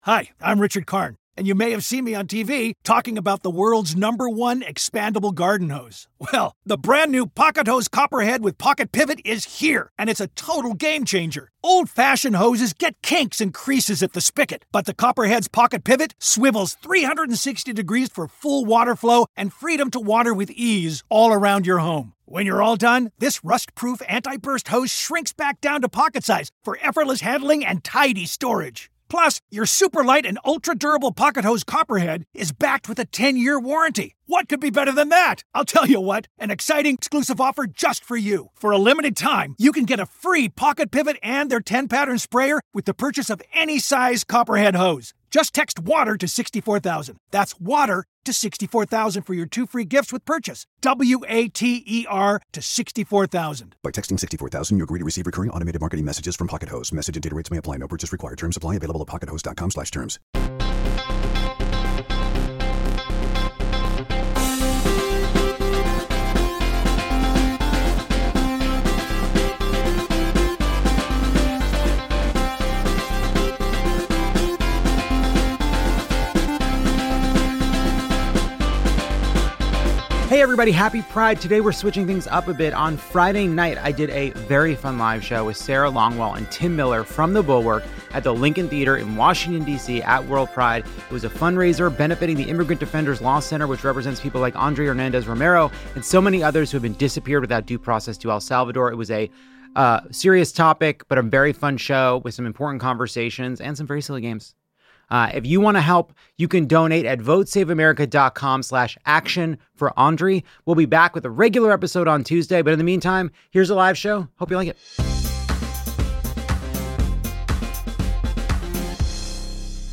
Lovett takes us to the library for some good old fashioned reads of the Trump administration with help from the audience.